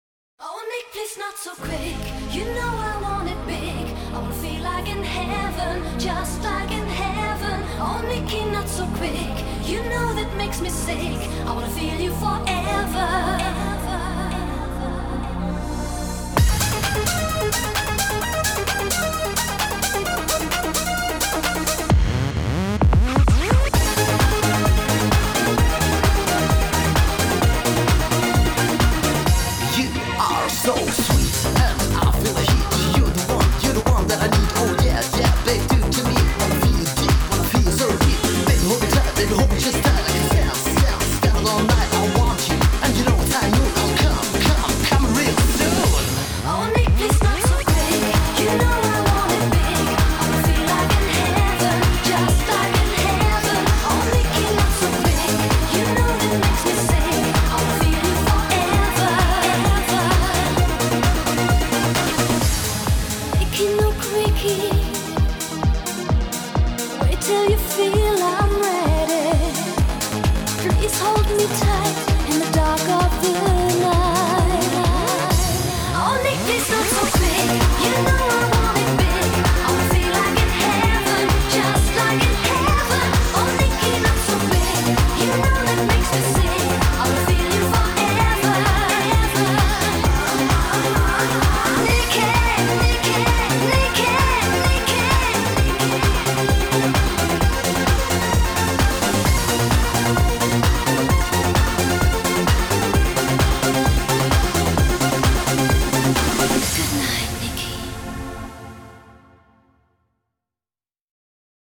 BPM130
Audio QualityMusic Cut